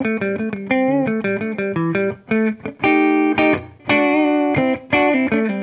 Index of /musicradar/sampled-funk-soul-samples/85bpm/Guitar
SSF_TeleGuitarProc1_85A.wav